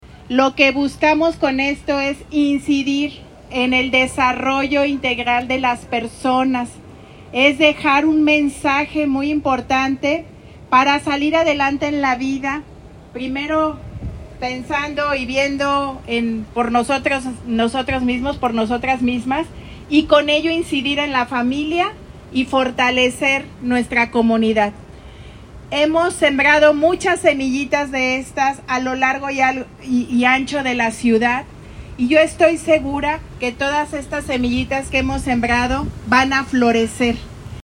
Lorena Alfaro García, presidenta munciipal